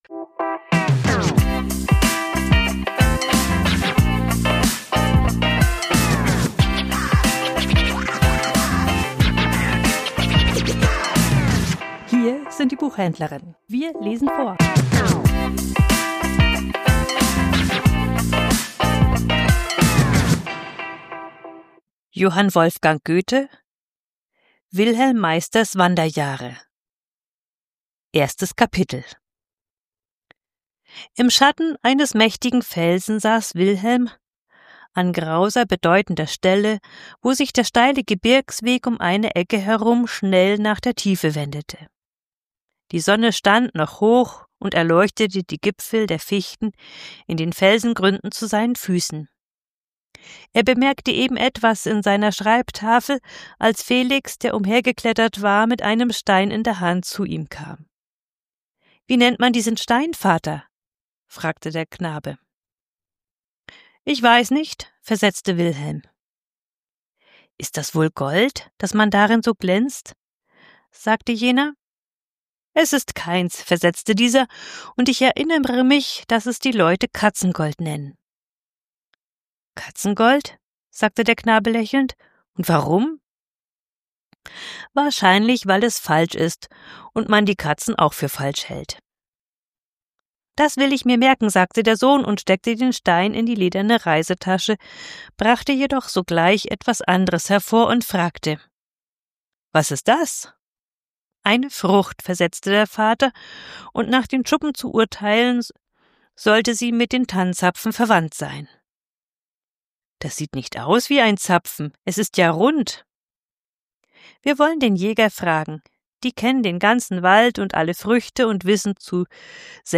Vorgelesen: Wilhelm Meisters Wanderjahre ~ Die Buchhändlerinnen Podcast